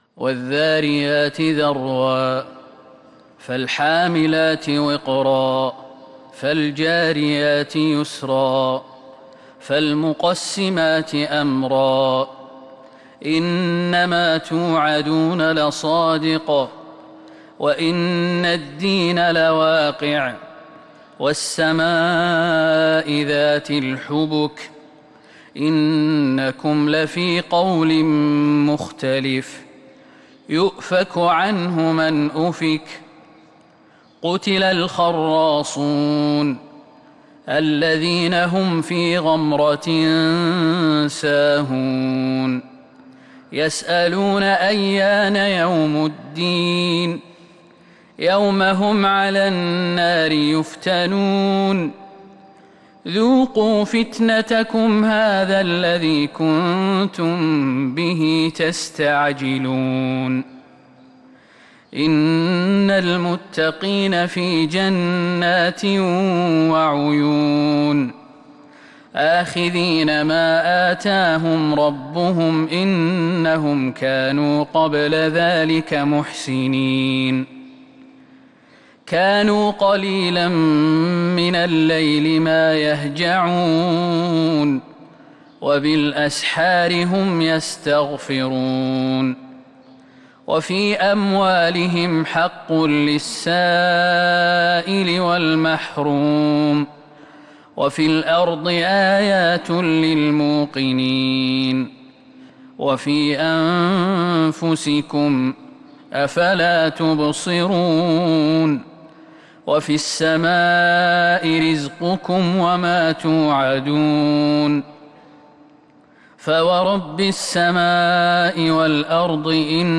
فجر الاثنين 9-8-1442هـ من سورة الذاريات | Fajr prayer from surat Adh-Dhariyat 22/3/2021 > 1442 🕌 > الفروض - تلاوات الحرمين